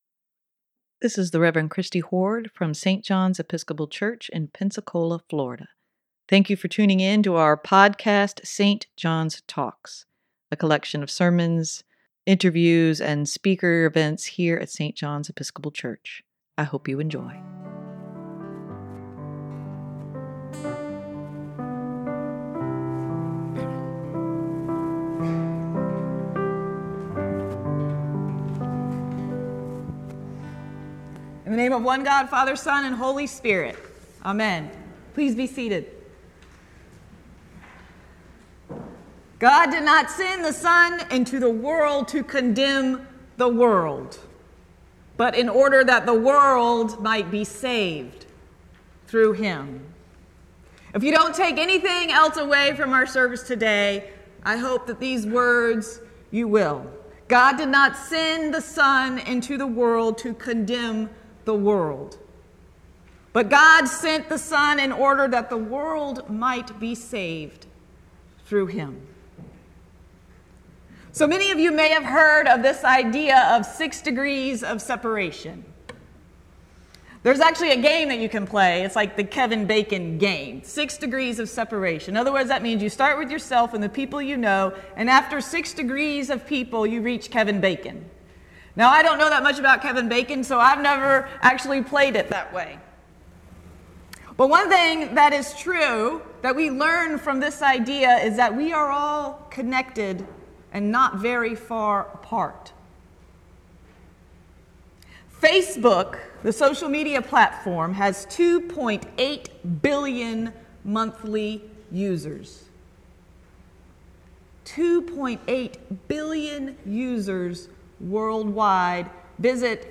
Sermon for Sunday, May 30, 2021 - St. John's Episcopal Church